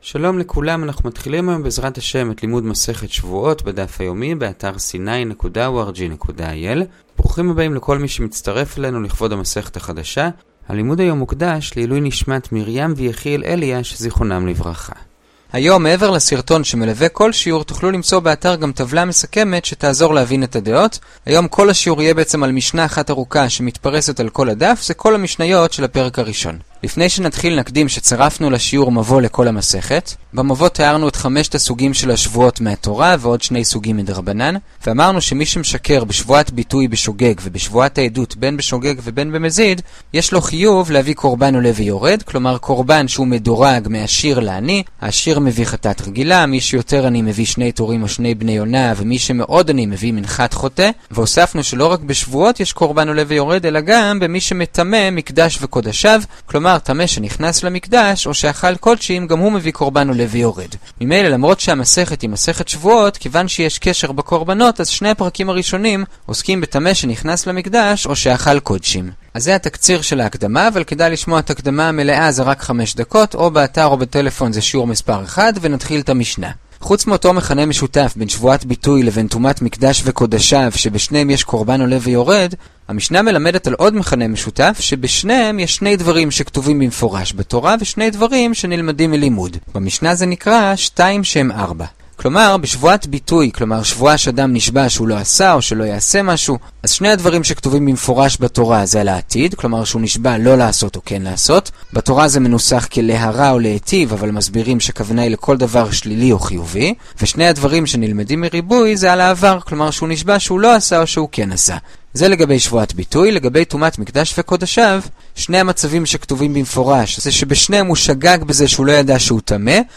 שבועות דף ב, גמרא הדף היומי - הדף היומי ב15 דקות - שיעורי דף יומי קצרים בגמרא